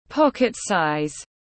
Nhỏ cỡ bỏ túi tiếng anh gọi là pocket-size, phiên âm tiếng anh đọc là /ˈpɑk·ɪt ˌsɑɪz/ .
Pocket-size /ˈpɑk·ɪt ˌsɑɪz/